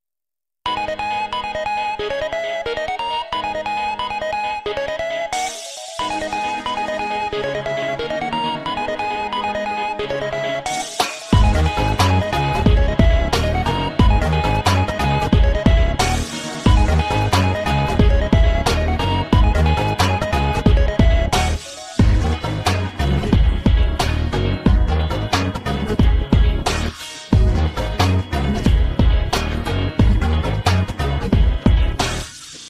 best flute ringtone download
dance ringtone download